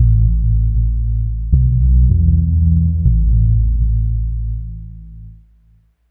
synth03.wav